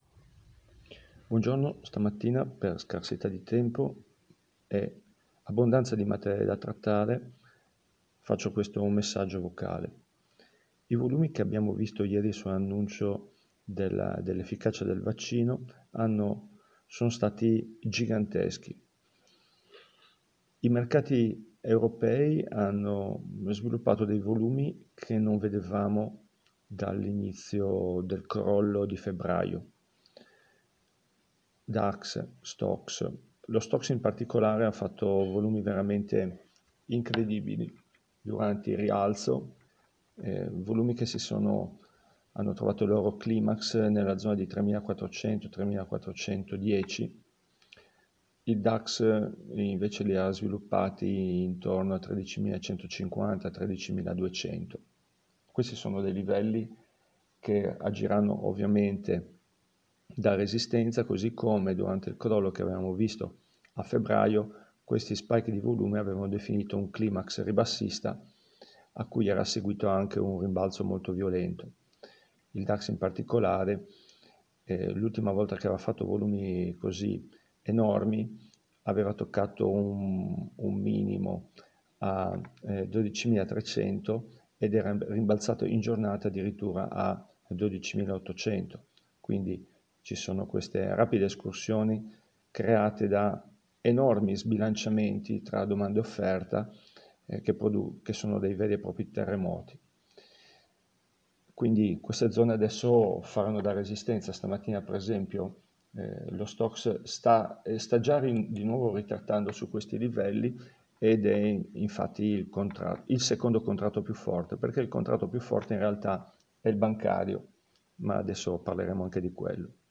ci sono troppe cose da scrivere per il movimento di ieri, ho reigstrato un commento vocale di 8' LINK http